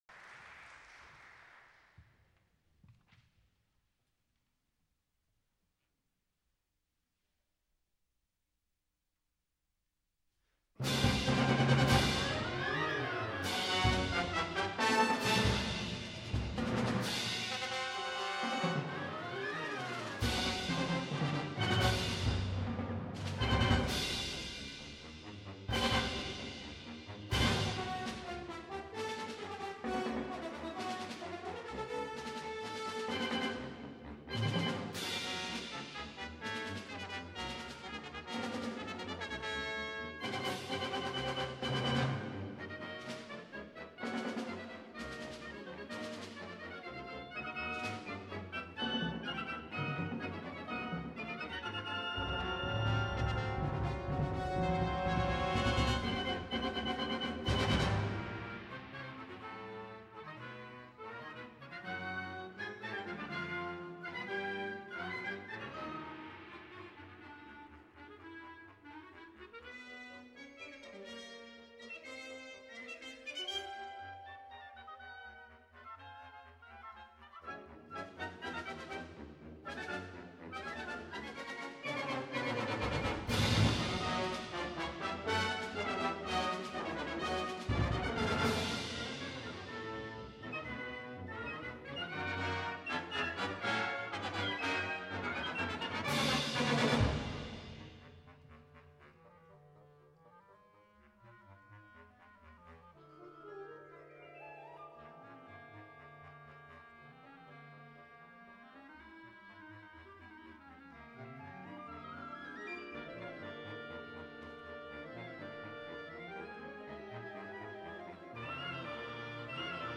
Genre: Band
Fast & furious
Percussion 1 (4 tom-toms, marimba, timpani)
Percussion 2 (bass drum, gong)
Percussion 3 (snare drum, vibraphone)